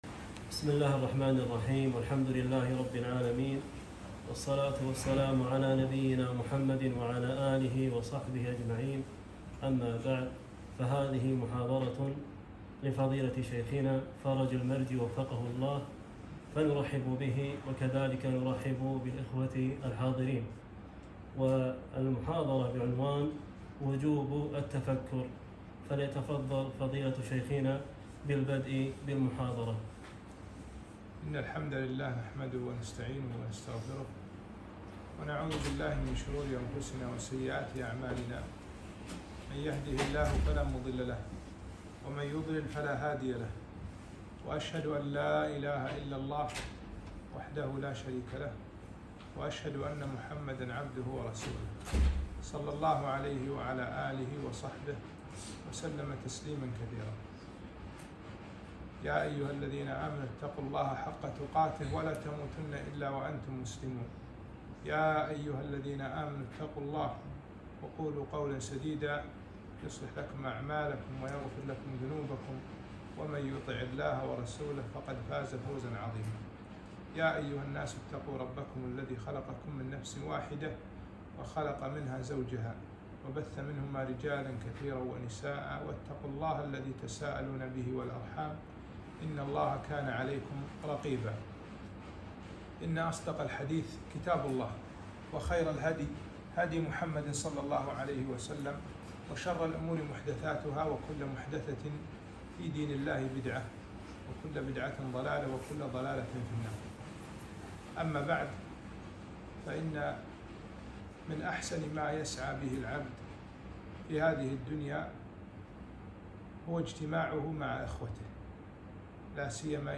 محاضرة - وجوب التفكر